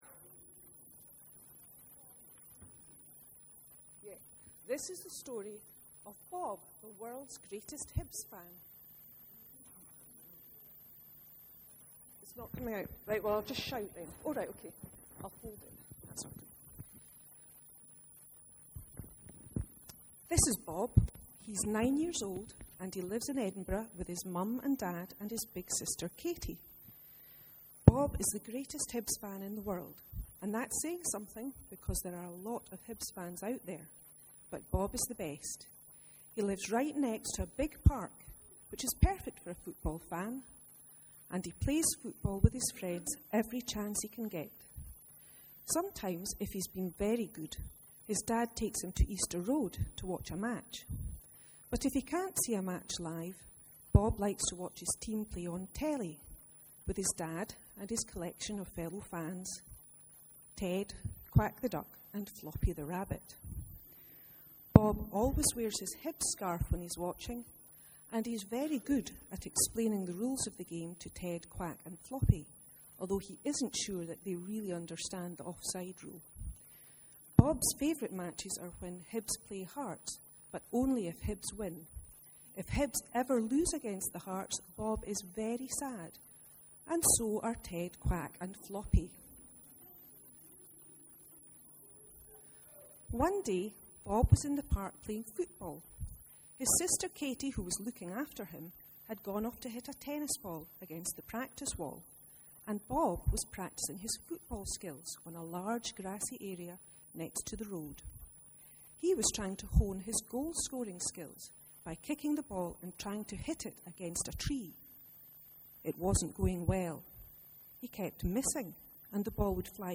02/06/13 sermon – The Parable of the Good Samaritan, and the issue of dignity (Luke 10:25-37).